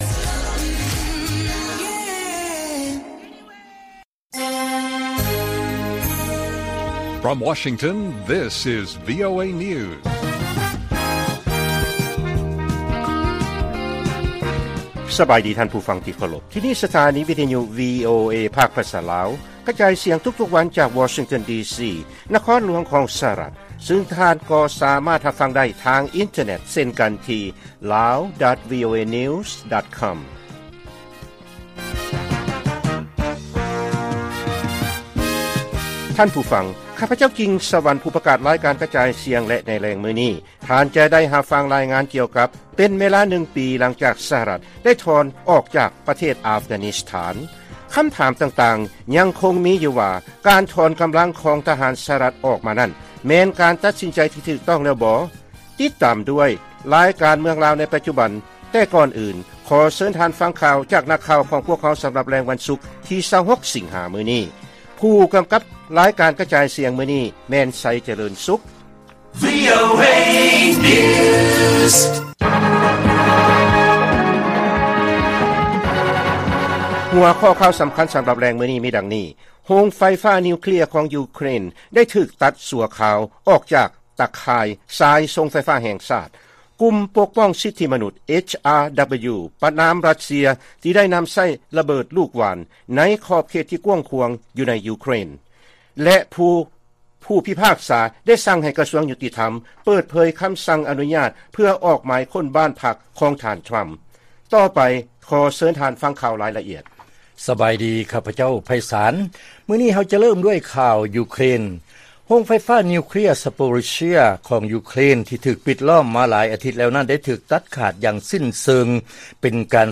ລາຍການກະຈາຍສຽງຂອງວີໂອເອ ລາວ: ໂຮງໄຟຟ້ານິວເຄລຍຂອງຢູເຄຣນ ໄດ້ຖືກຕັດຊົ່ວຄາວ ອອກຈາກຕາຂ່າຍສາຍສົ່ງໄຟຟ້າແຫ່ງຊາດ